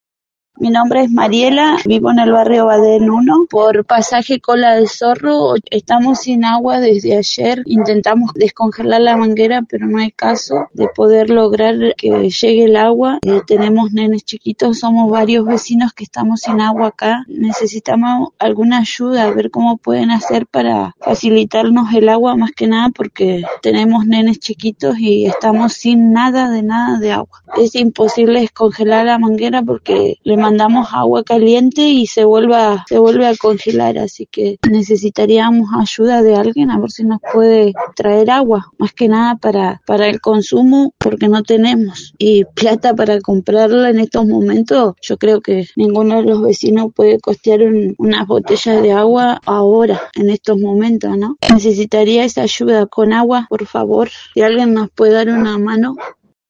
Luego de varios días de temperaturas heladas que superaron los 15° bajo cero, en diferentes sectores de la ciudad los vecinos comenzaron a informar sobre la falta de agua por las cañerías congeladas. Noticias de Esquel estuvo en contacto con una vecina del barrio Badén I que solicitaba ayuda con agua, ya que varias familias con niños se encuentran sin el suministro del vital elemento.